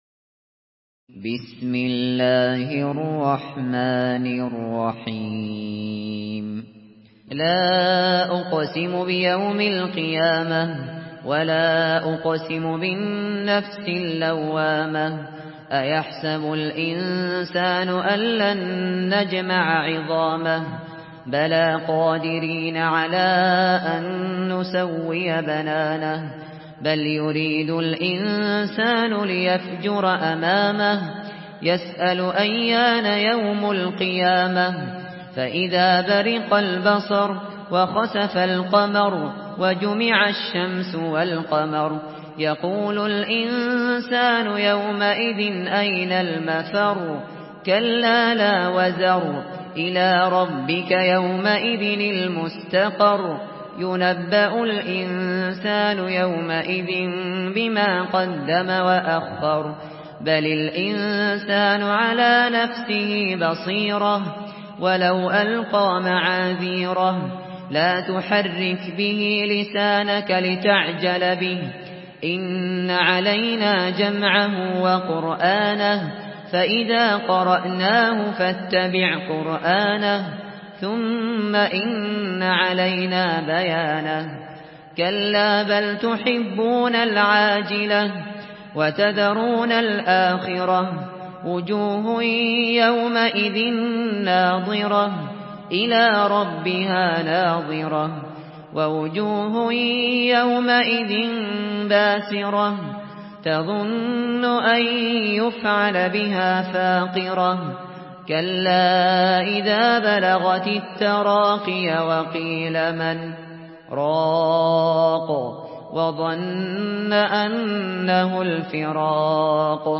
Surah Kiyame MP3 in the Voice of Abu Bakr Al Shatri in Hafs Narration
Surah Kiyame MP3 by Abu Bakr Al Shatri in Hafs An Asim narration.
Murattal Hafs An Asim